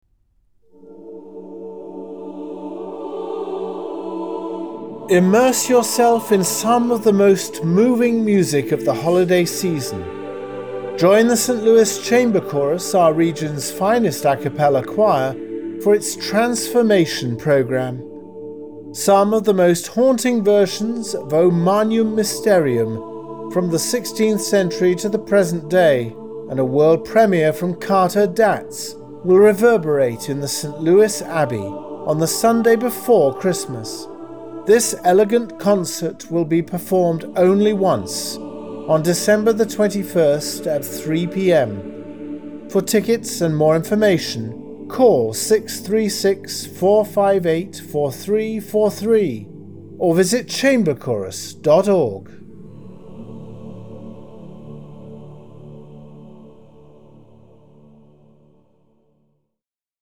The Midwest's premier a cappella ensemble, the Saint Louis Chamber Chorus presents the finest a cappella choral works through its unique programming.